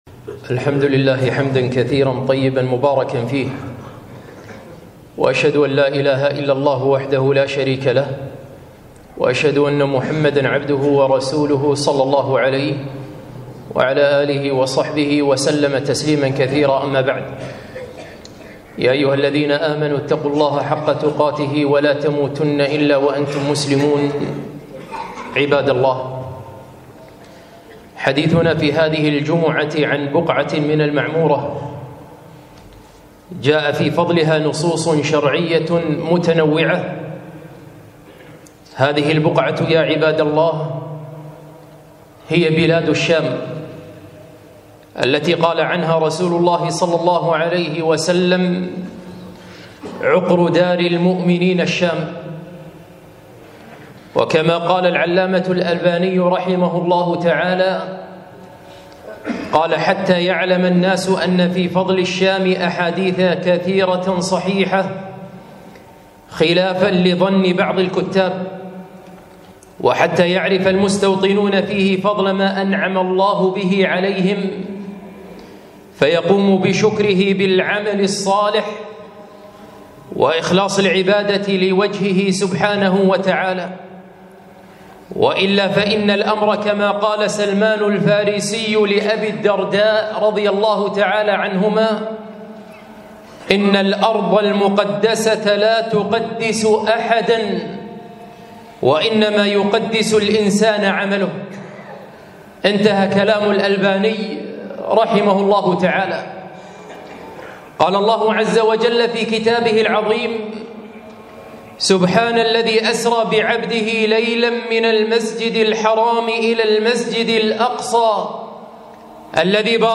خطبة - فضائل الشام